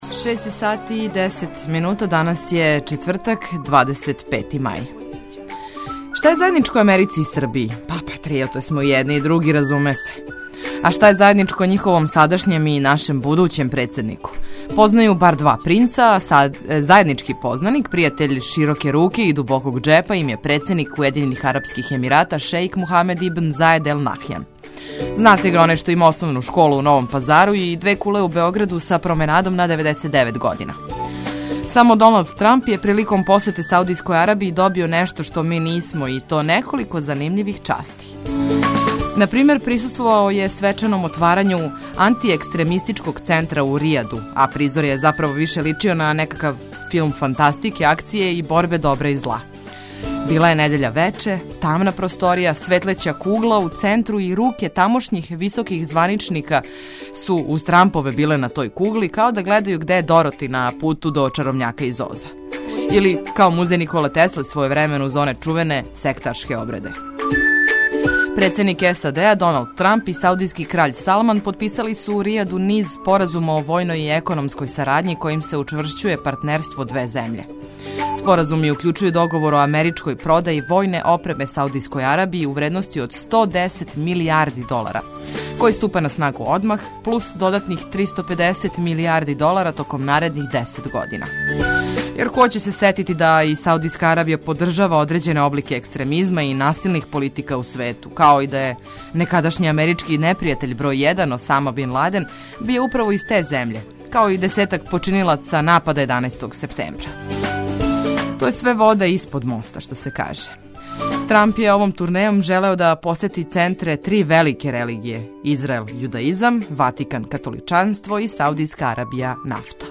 Ведра музика, заразно добро расположење и корисне и важне информације - то је оно што нудимо у јутру Двестадвојке!